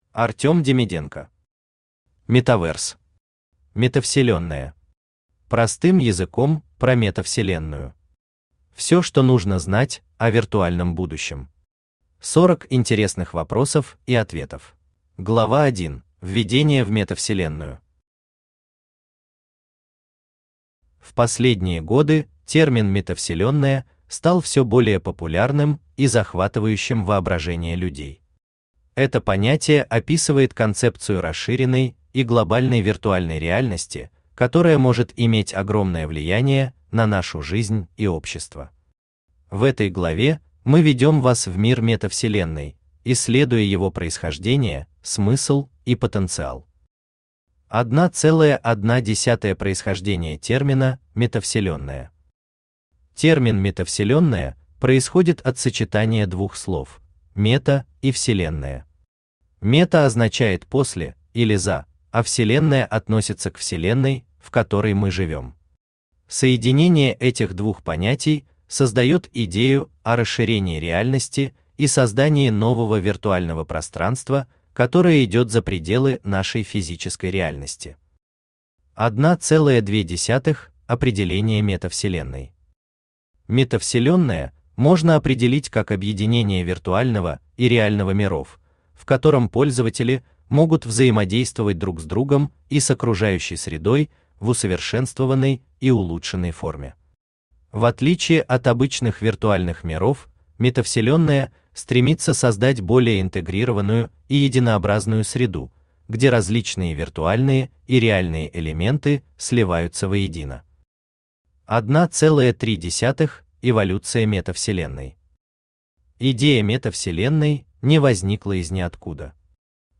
Аудиокнига Metaverse. Метавселенная.
Автор Артем Демиденко Читает аудиокнигу Авточтец ЛитРес.